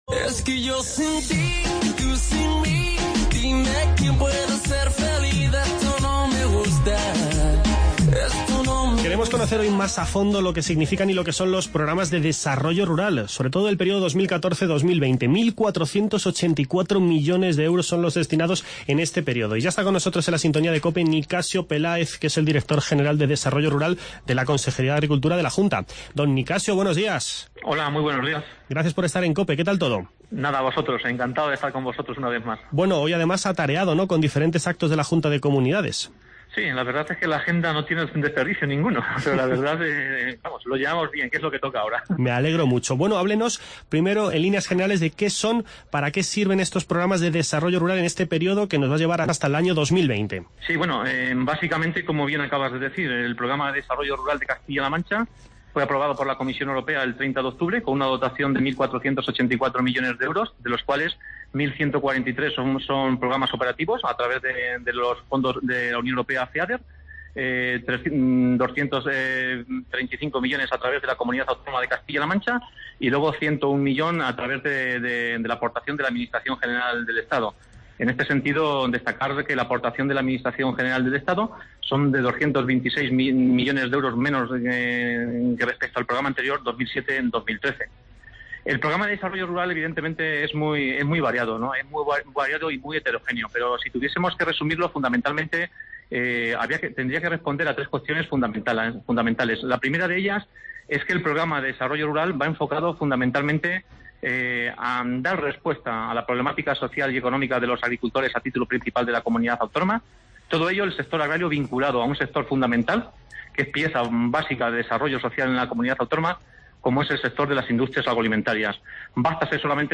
Analizamos el Programa de Desarrollo Rural 2014-2020 con Nicasio Peláez, director general de Desarrollo Rural de la Consejería de Agricultura de la Junta de Comunidades. Nicasio Peláez nos explica los tres pilares en los que se asienta dicho Programa de Desarrollo Rural cuyo montante económico para este periodo asciende a 1.484 millones de euros. A continuación, hablamos de la diabetes.